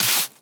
Broom Sweeping
sweeping_broom_leaves_stones_11.wav